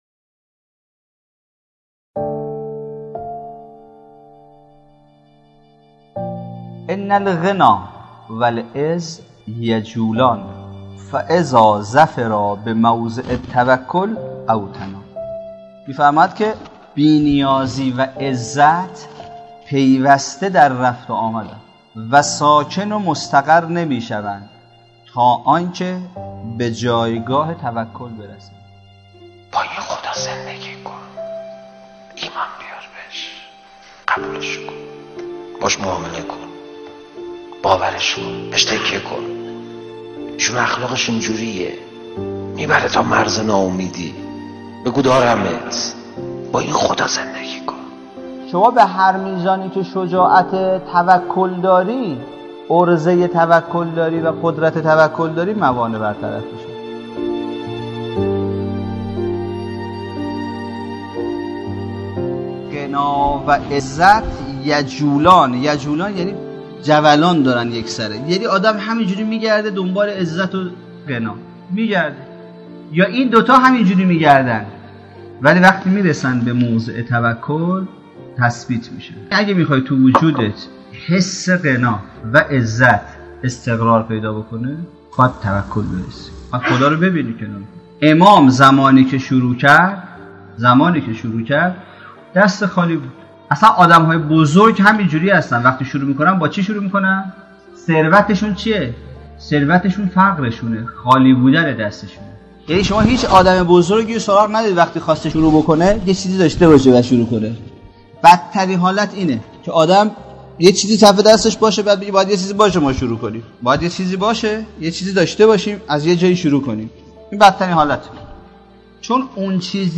مدت سخنرانی : 11 دقیقه